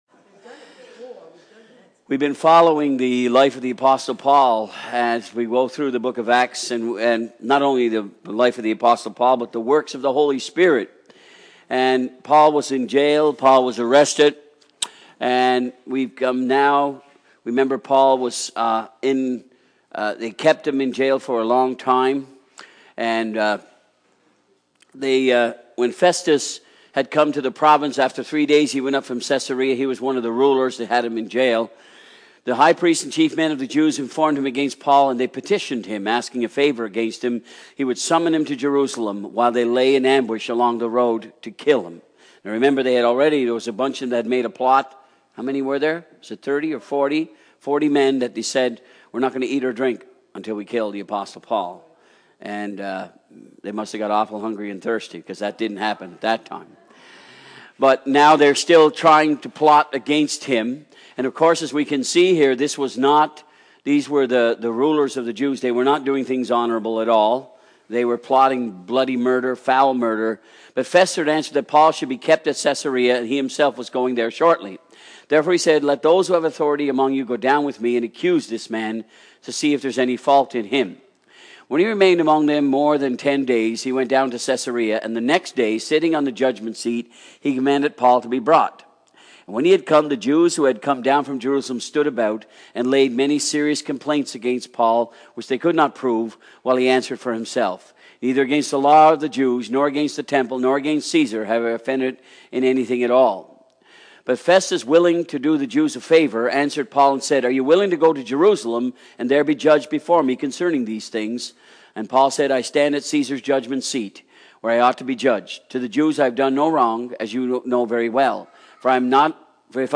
Sermons from Acts